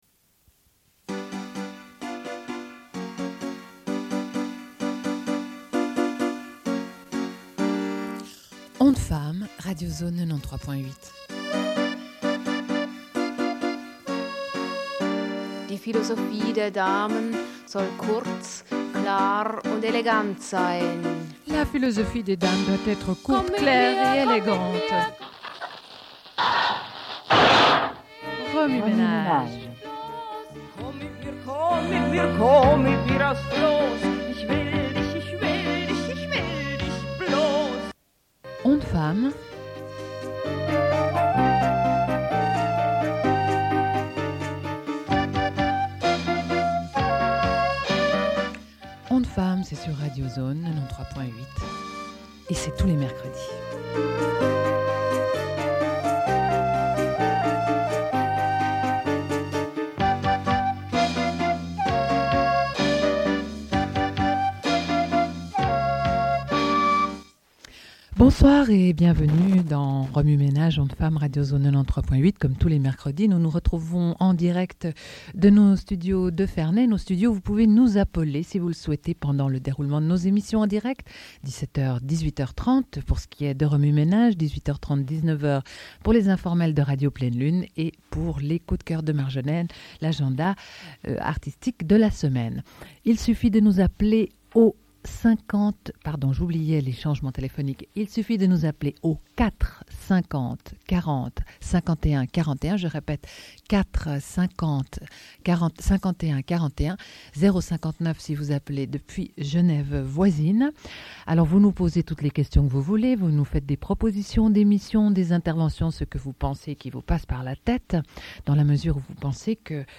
Lecture du texte du personnel.
Une cassette audio, face A
Radio Enregistrement sonore